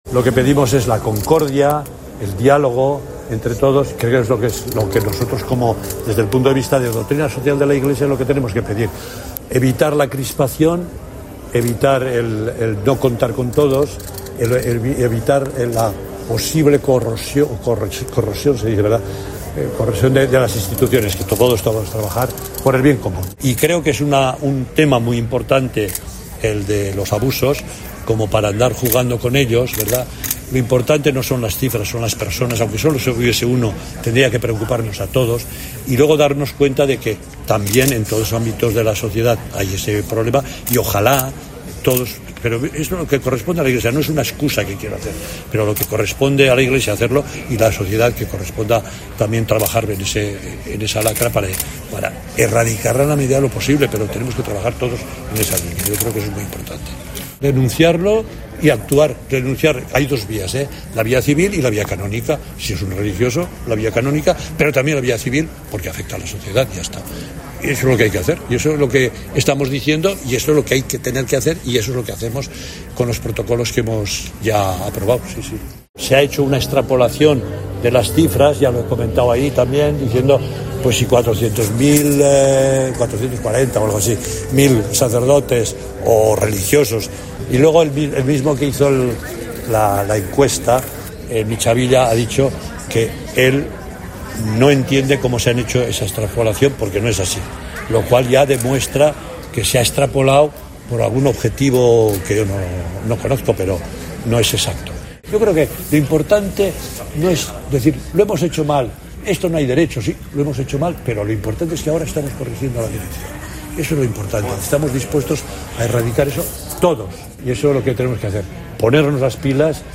El presidente de la Conferencia Episcopal, el cardenal Juan José Omella, se ha referido a los abusos cometidos en el seno de la Iglesia, en el III Encuentro de Laicos de Aragón
El cardenal Juan José Omella ha participado en Zaragoza en el III Encuentro de Laicos de Aragón